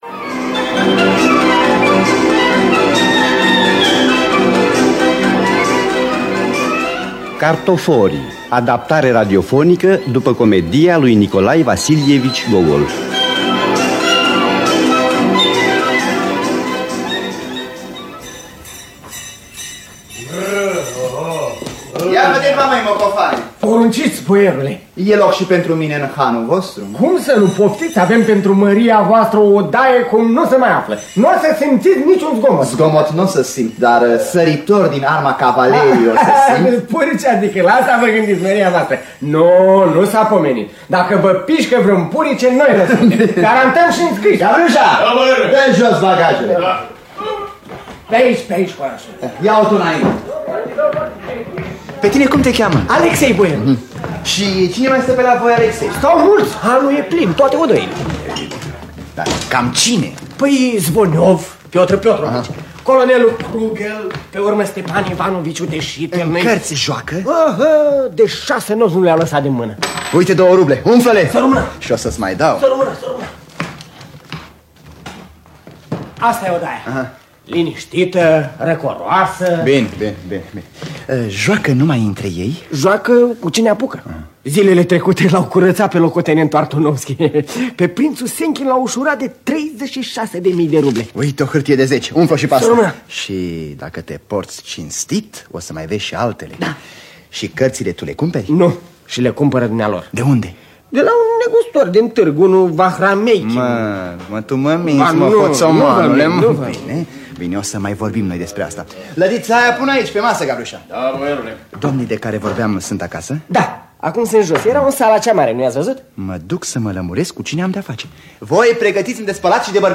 Cartoforii sau Jucătorii de cărți de Nikolai Vasilievici Gogol – Teatru Radiofonic Online